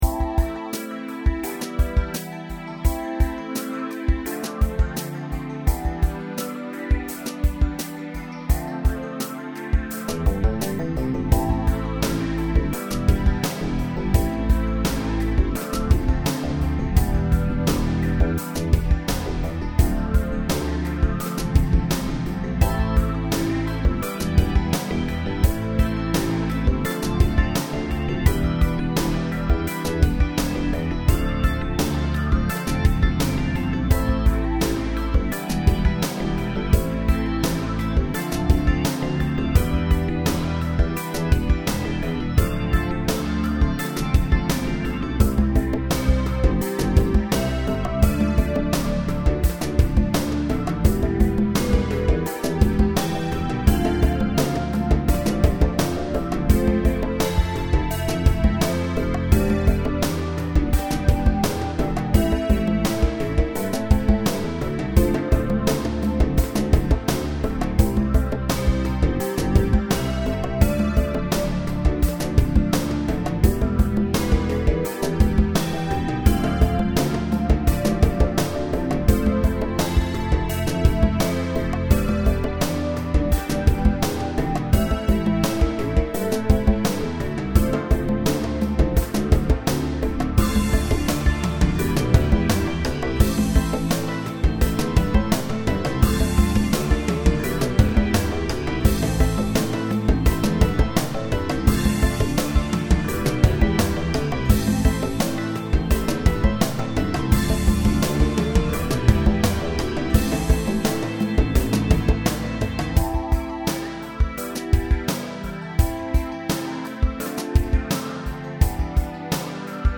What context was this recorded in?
I made this with garageband on my dad's old mac mini from 15 years ago.